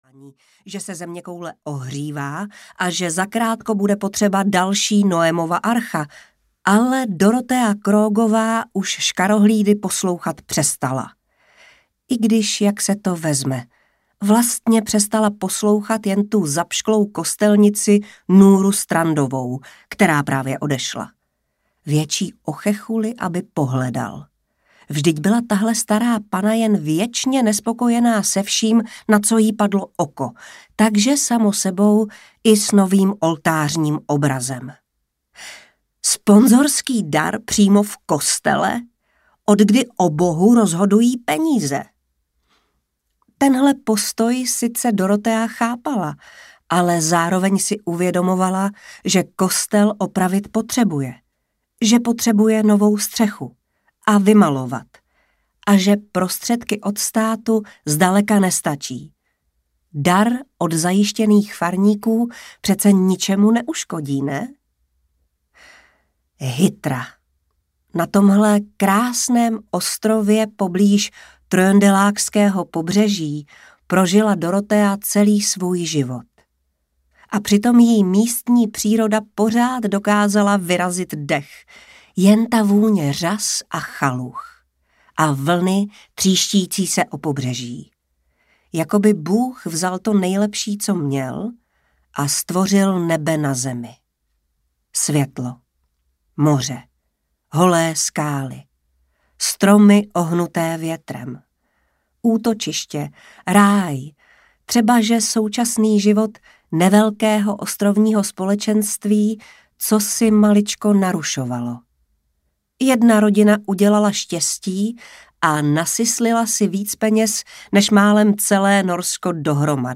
Ostrov audiokniha
Ukázka z knihy